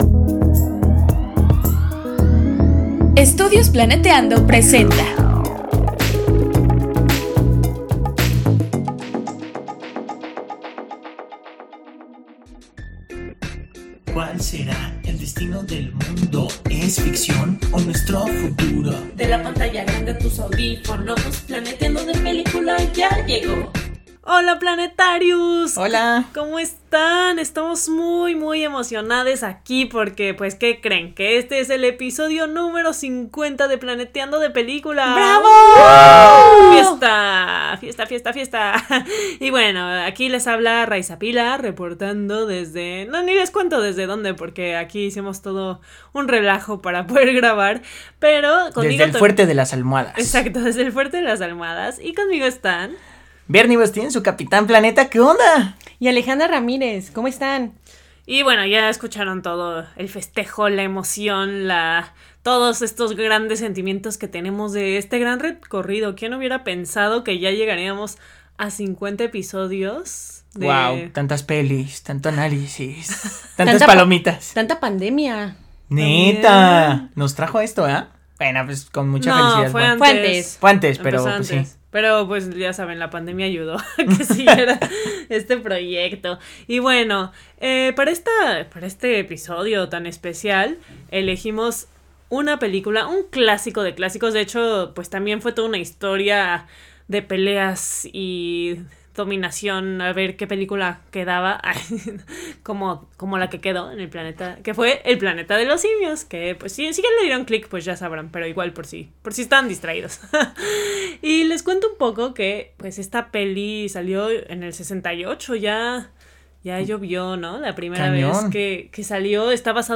¡No te pierdas esta gran conversación!